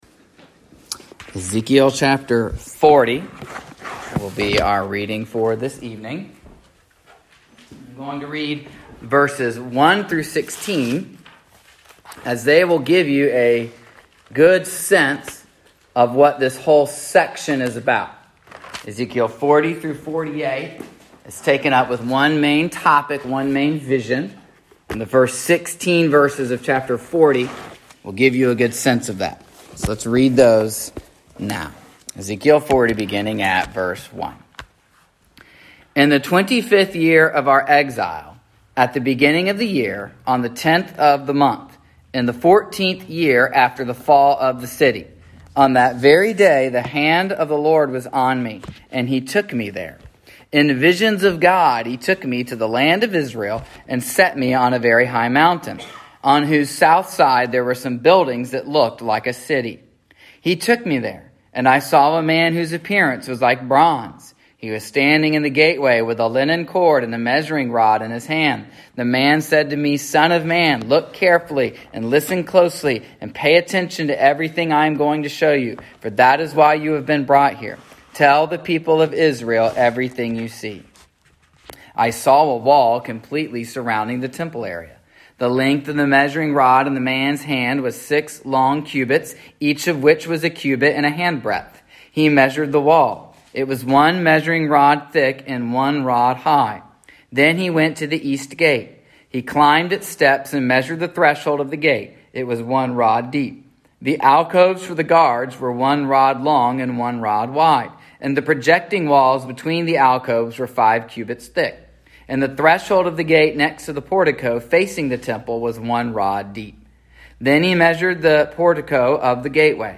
In this sermon we look at how God acts to restore and renew his worship. We also look at how the promises God makes to Israel begin to be restored during the ministry of the Lord Jesus Christ.